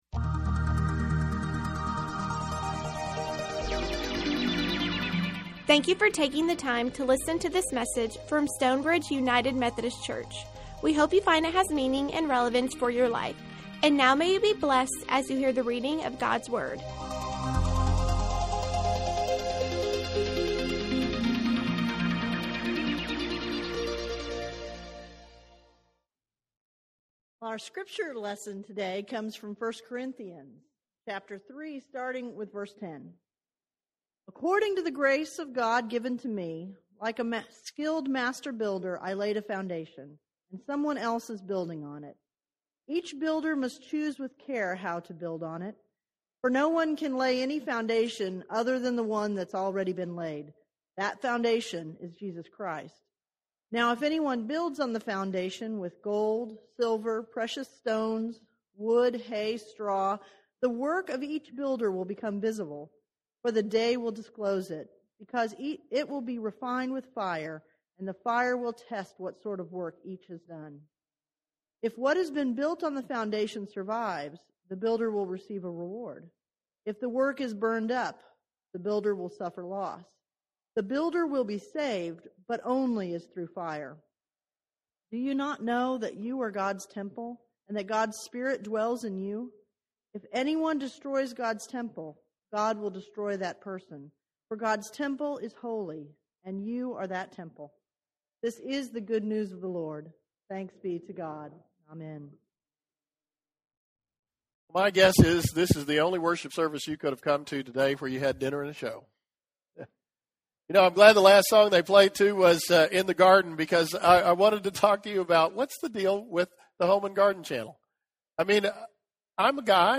On this debt reduction commitment Sunday he speaks of the church being the foundation to carry out Jesus' work. Recorded live at Stonebridge United Methodist Church in McKinney, TX.